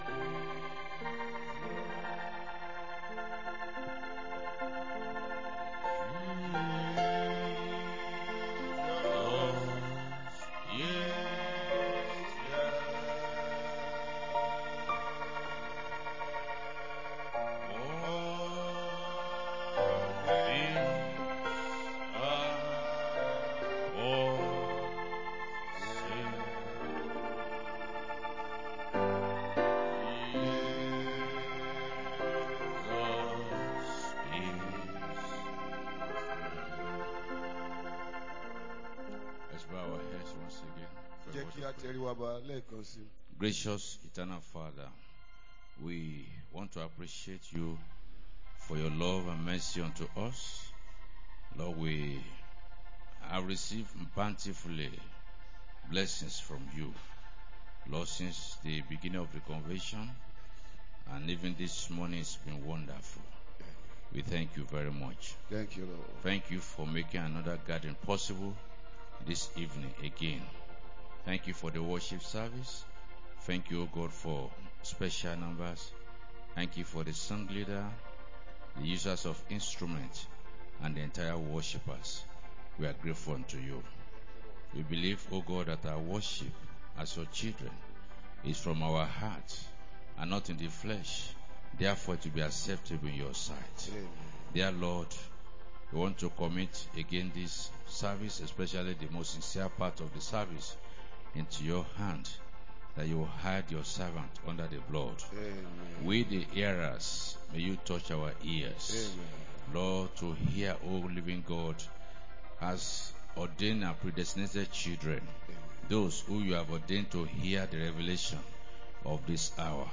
2025 Believers' Convention | 06-09-25 | Evening Service